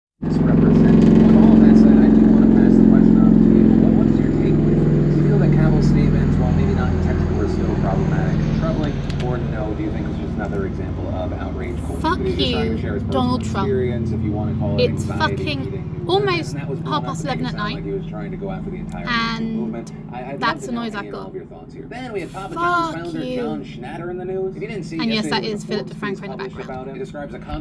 Donald Trump comes calling and national security is going crazy, So many helicopters and sirens.